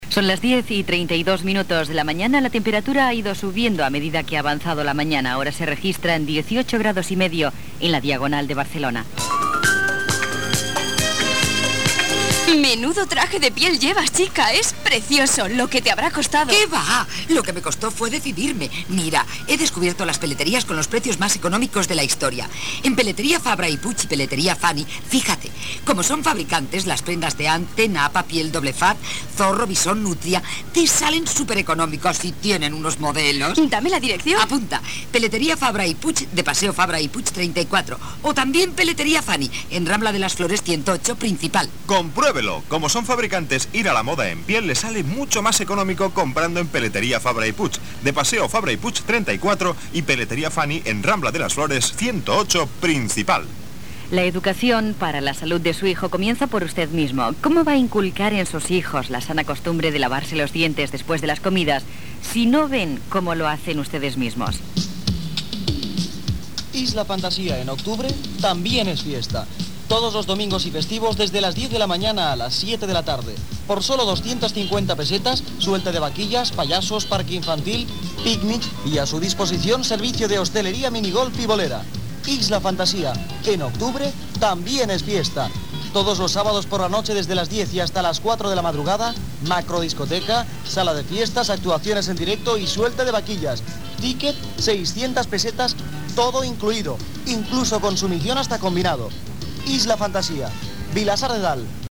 Gènere radiofònic Publicitat Anunciant Peletería Fany